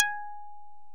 Index of /90_sSampleCDs/OMI - Universe of Sounds/EII Factory Library/85 Fretless Bass&Plucked Piano